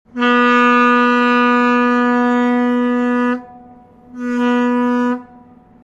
Parohod.mp3